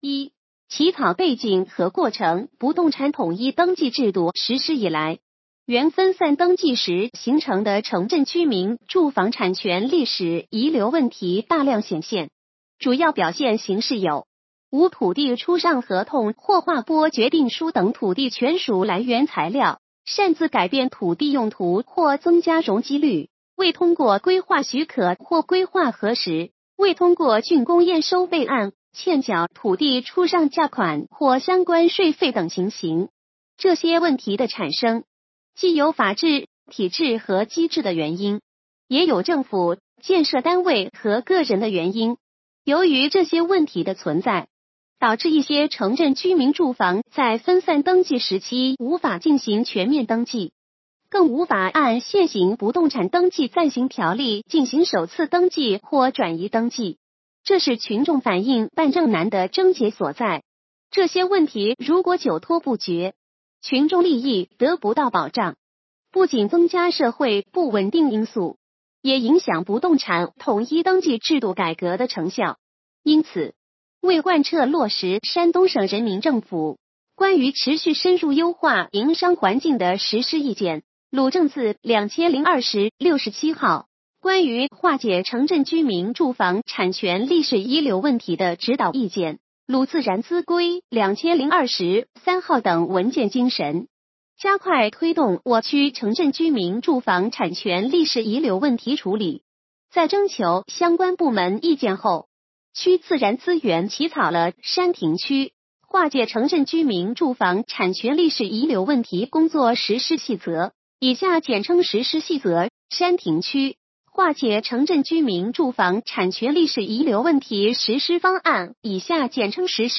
语音解读：山亭区人民政府办公室关于印发山亭区化解城镇居民住房产权历史遗留问题实施方案和山亭区化解城镇居民住房产权历史遗留问题工作实施细则的通知